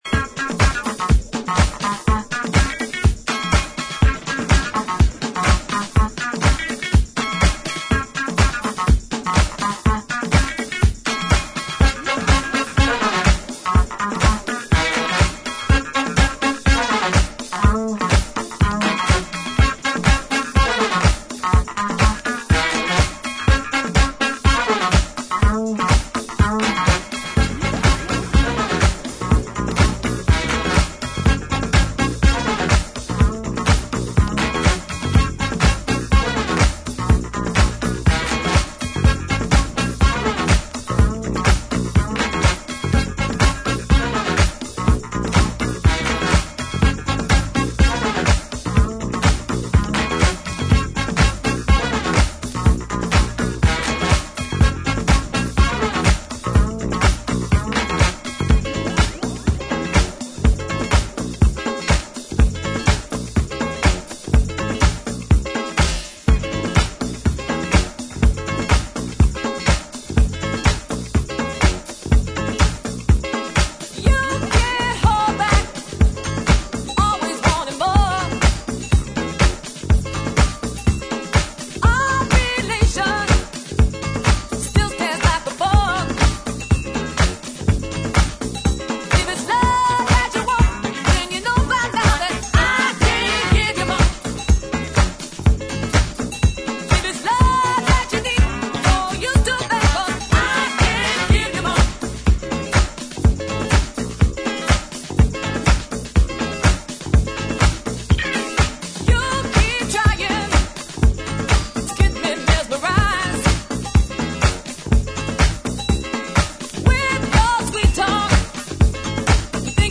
ジャンル(スタイル) DISCO / SOUL / FUNK / NU DISCO / RE-EDIT